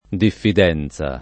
diffidenza [ diffid $ n Z a ] s. f.